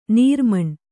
♪ nīrmaṇ